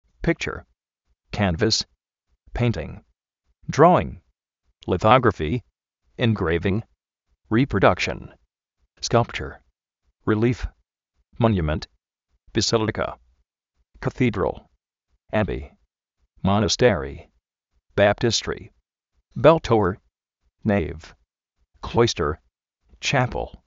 píkcher, kánvas, péintin,
dró:in, lizógrafi, engréivin, riprodákshn, skálpcher,
rilíf, móniument
besílika, kazídral, á:bi, monastéri, baptístri,
bél-tóuer, néiv, klóister,
chápl